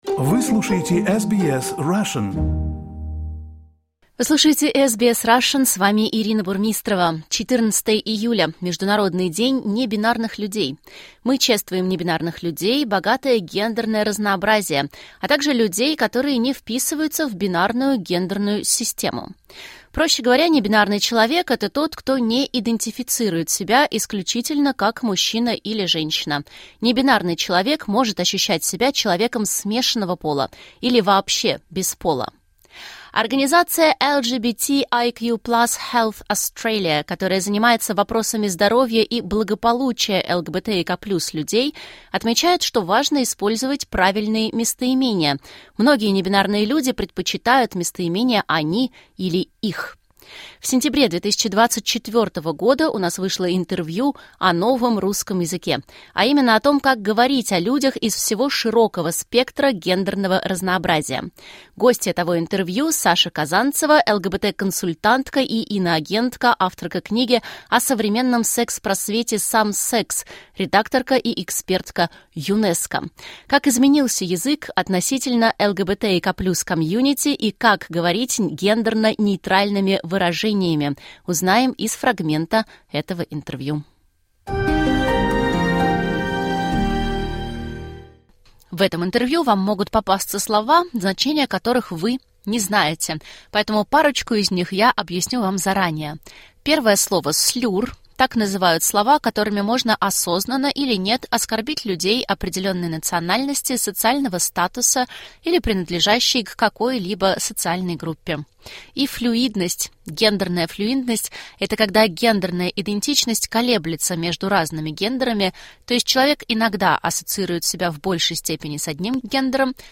Организация LGBTIQ+ Health Australia (LHA), которая занимается вопросами здоровья и благополучия ЛГБТИК+ людей, отмечает, что важно использовать правильные местоимения. Вспоминаем интервью о том, как говорить о людях из всего широкого спектра гендерного разнообразия.